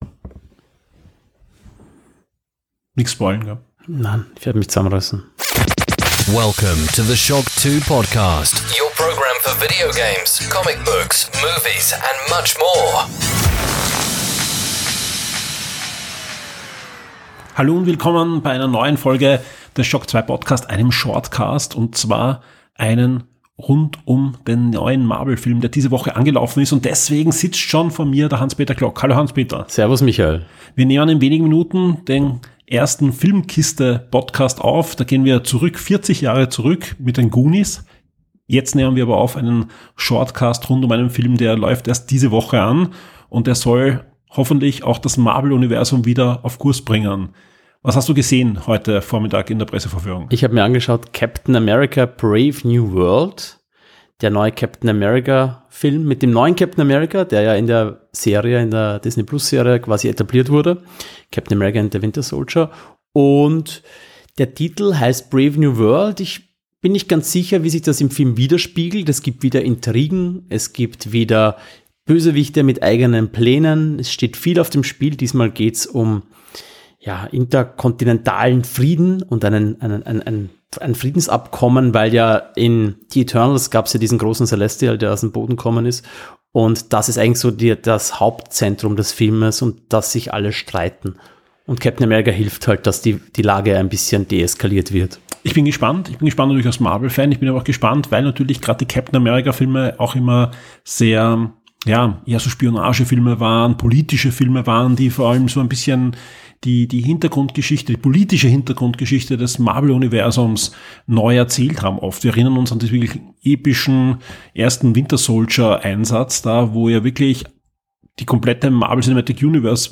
Shownotes 10 Minuten Audio-Review - Captain America: Brave New World (Spoilerfrei!) Ist Captain America: Brave New World endlich der Befreiungsschlag des MCU, auf den wir gewartet haben?